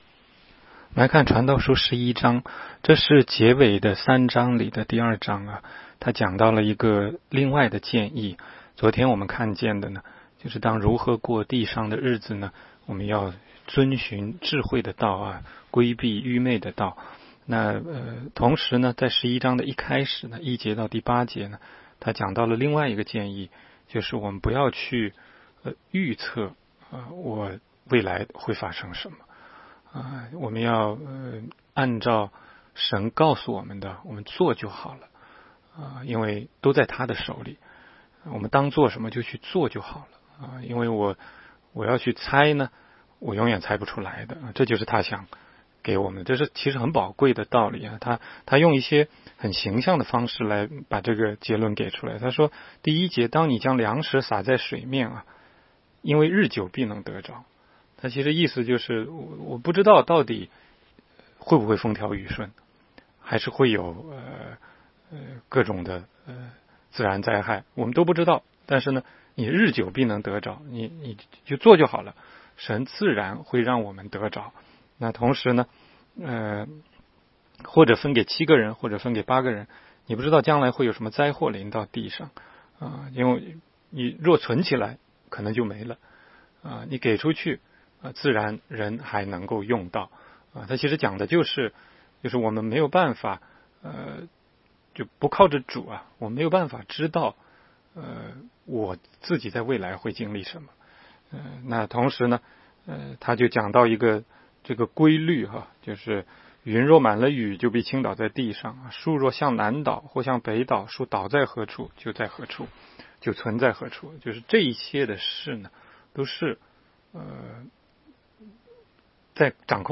16街讲道录音 - 每日读经 -《 传道书》11章